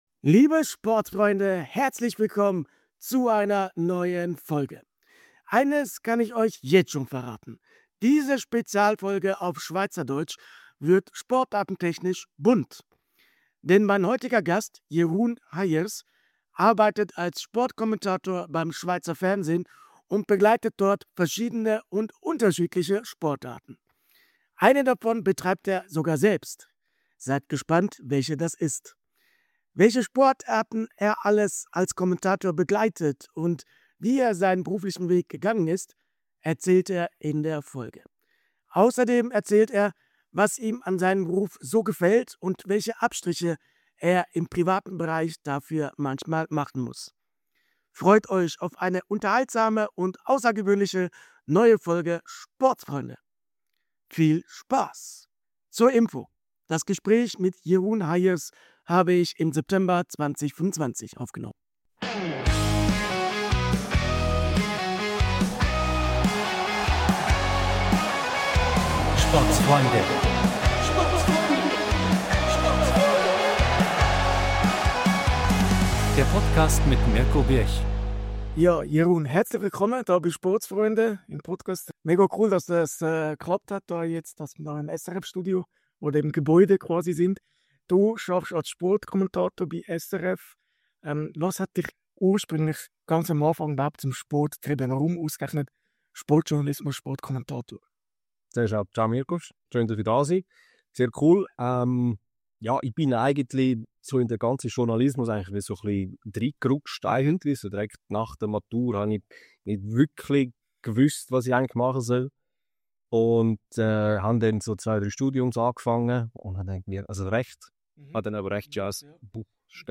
Spezialfolge auf Schweizerdeutsch! ~ Sportsfreunde Podcast